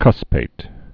(kŭspāt) also cus·pat·ed (-pātĭd)